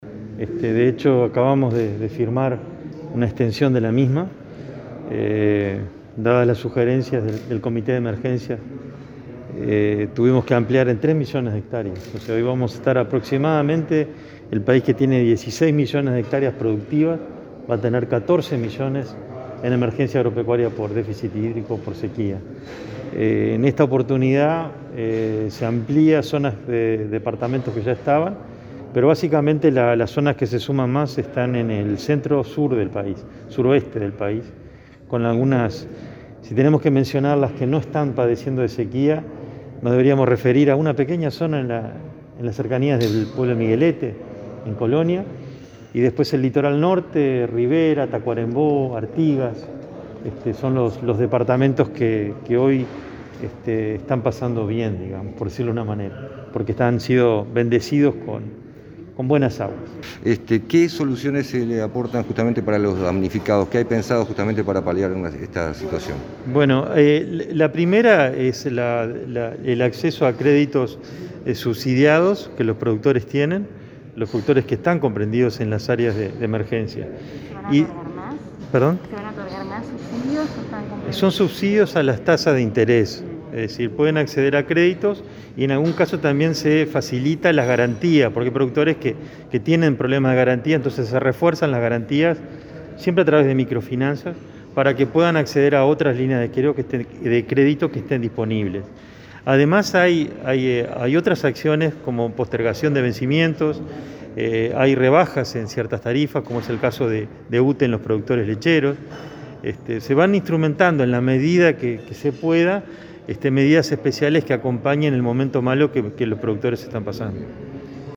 Declaraciones de Carlos María Uriarte, ministro de Ganadería